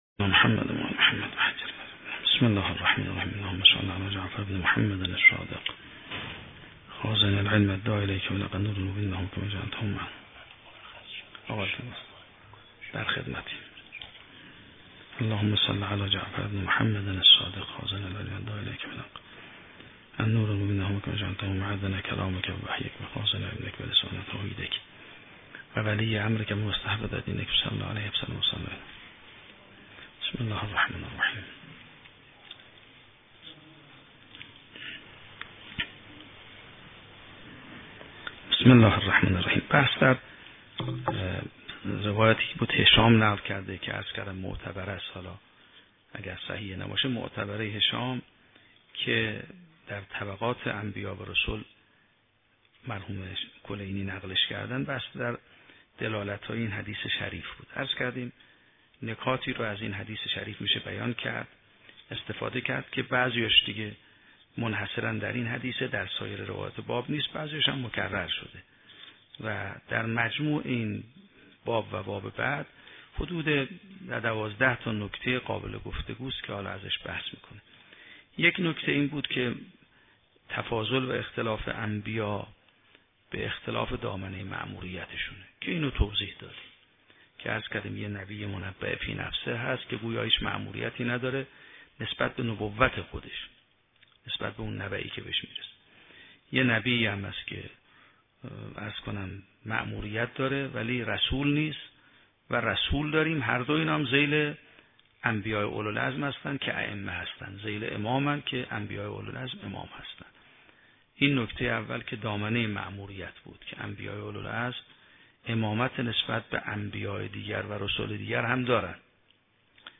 شرح و بررسی کتاب الحجه کافی توسط آیت الله سید محمدمهدی میرباقری به همراه متن سخنرانی ؛ این بخش : صحیحه هشام بن سالم در تبیین طبقات انبیاء و نکات کلیدی روایت